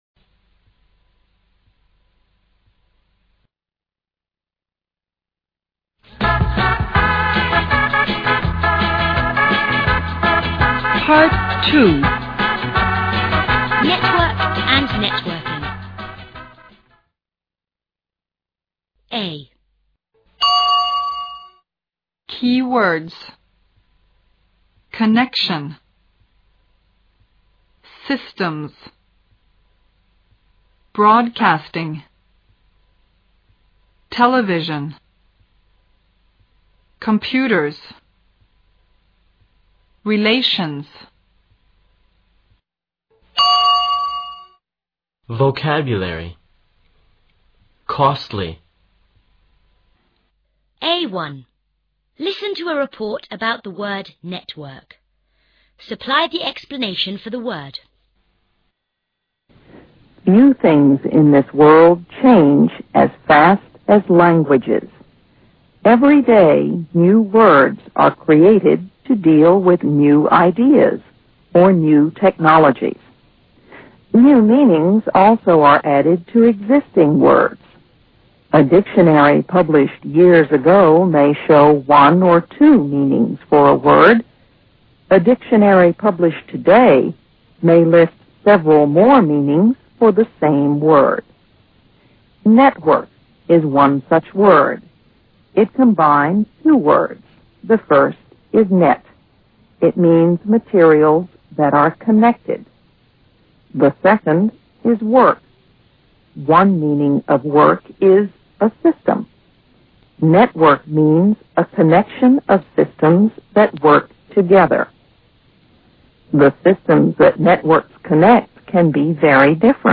A1. Listen to a report about the word "network".